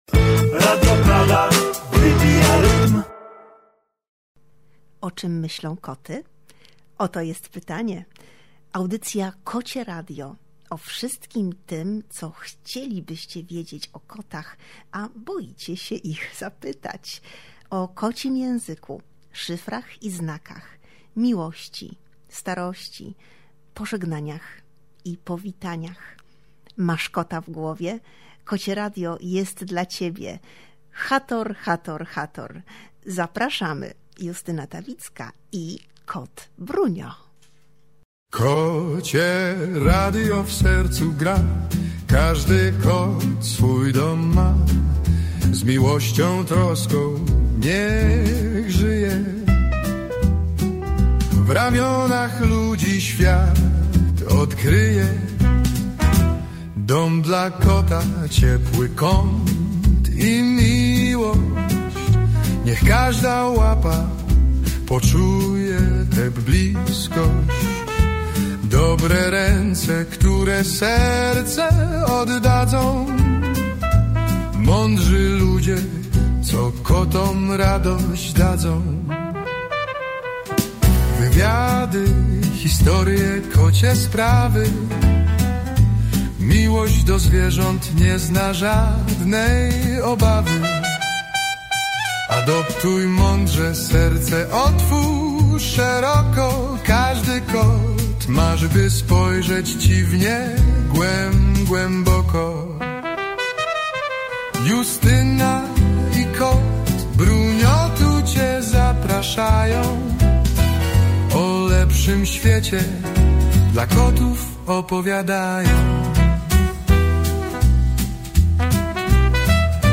W najnowszej audycji Kocie Radio gościło w wyjątkowym miejscu: to Ochota na Kota punkt adopcyjno-informacyjny schroniska „Na Paluchu”, mieszczący się przy ul. Grójeckiej 79 w Warszawie.